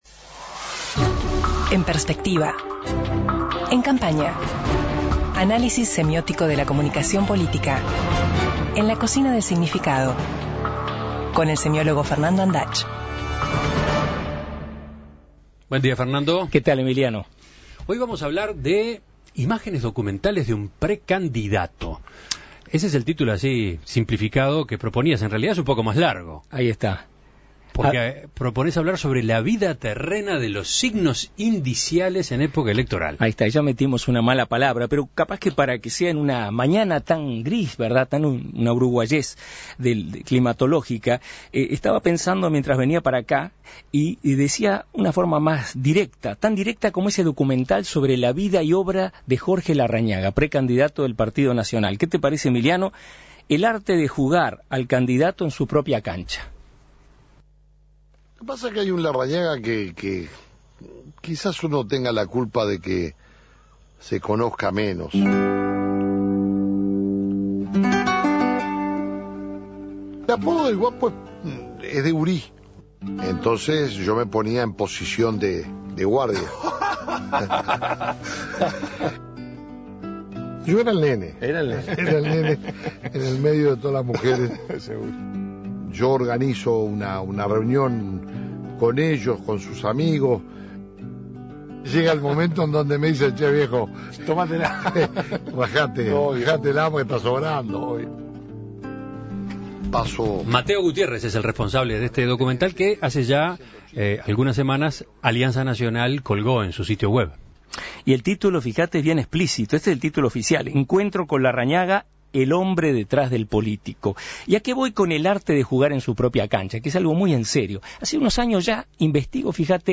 De recuerdos y tonadas rurales que van albergando relatos, epopeyas y crónicas cotidianas (emitido a las 10.41 hs.)